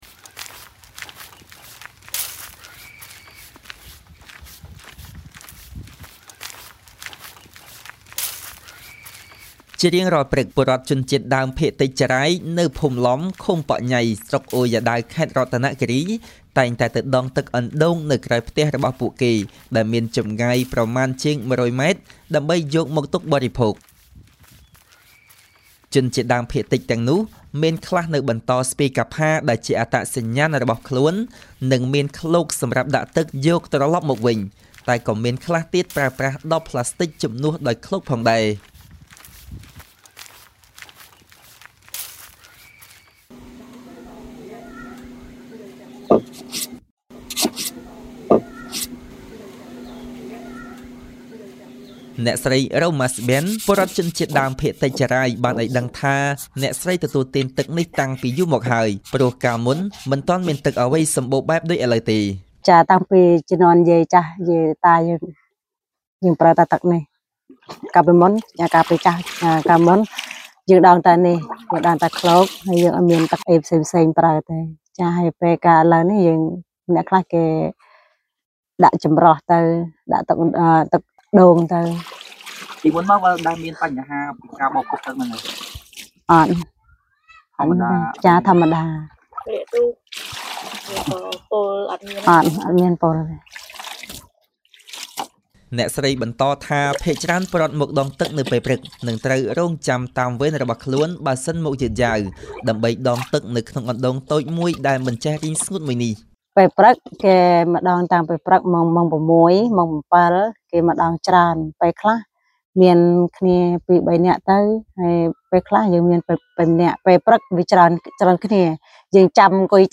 បទយកការណ៍